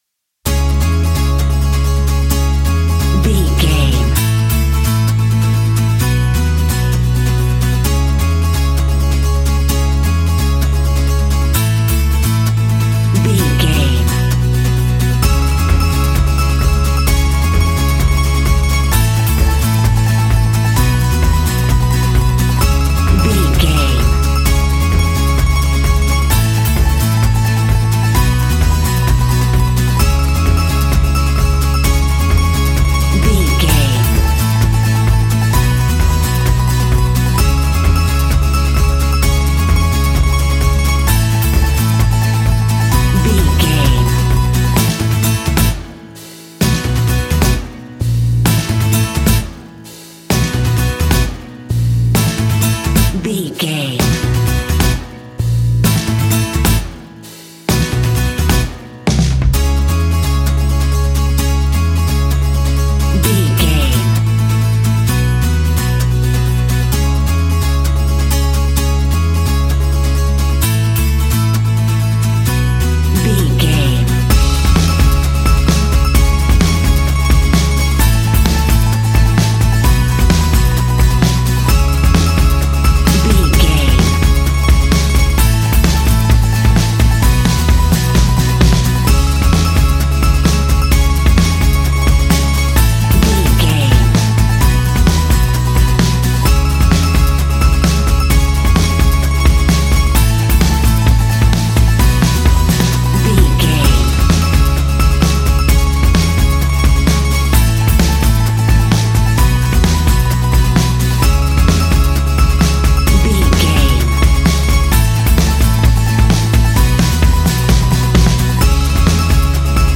Aeolian/Minor
C#
earthy
acoustic guitar
mandolin
ukulele
lapsteel
drums
double bass
accordion